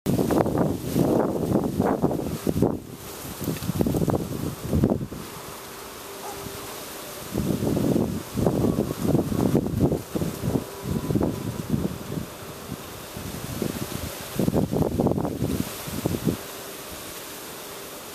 Small Wind